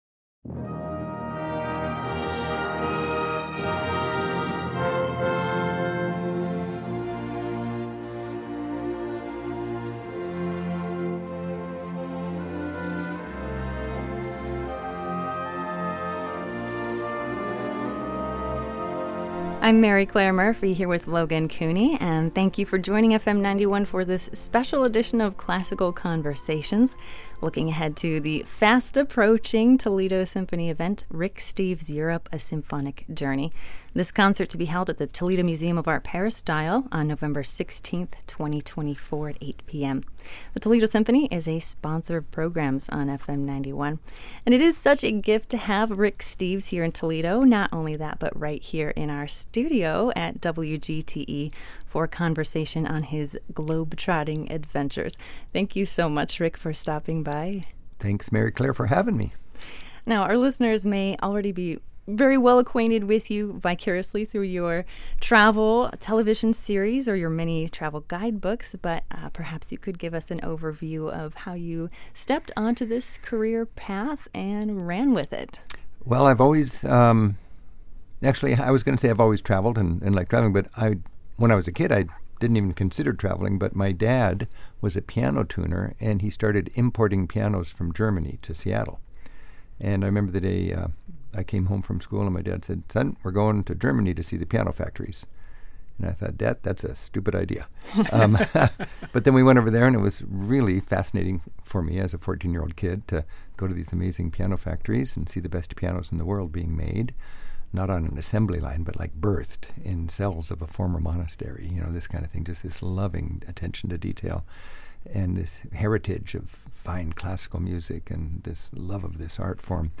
Classical-Conversation-with-Rick-Steves-Export_0.mp3